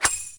ring.ogg